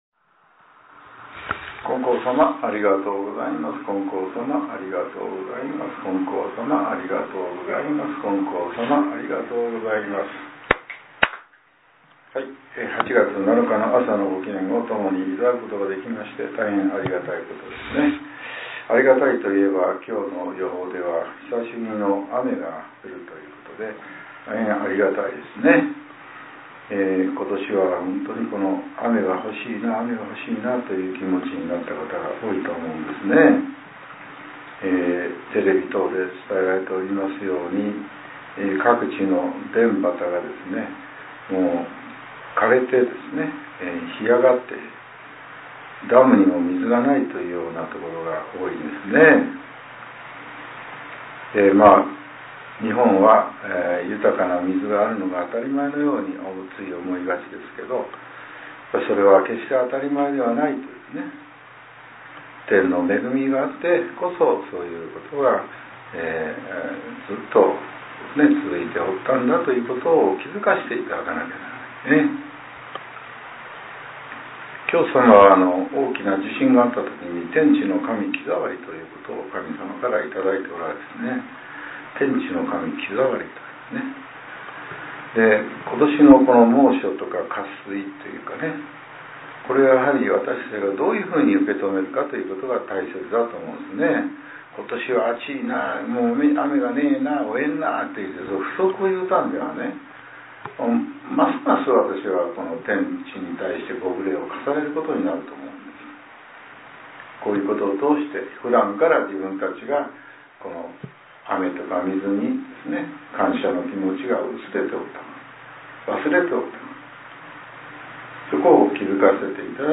令和７年８月７日（朝）のお話が、音声ブログとして更新させれています。